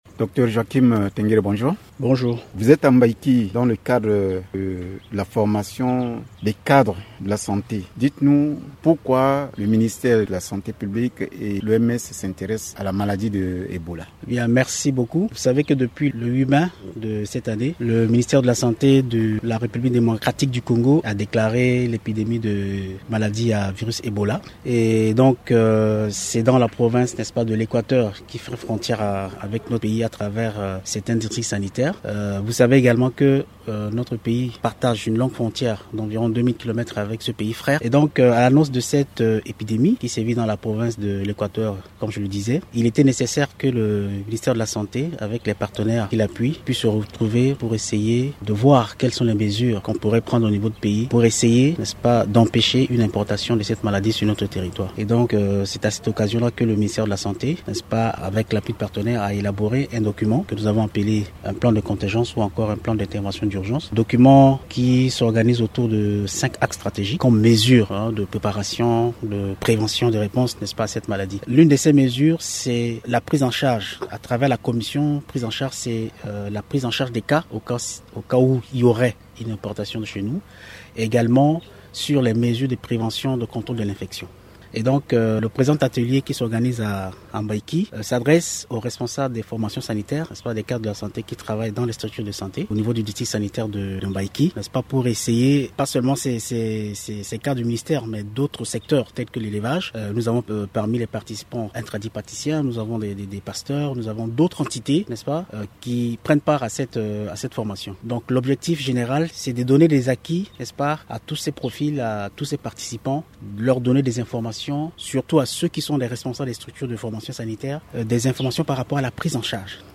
lors d’une rencontre stratégique à Mbaïki dans la Lobaye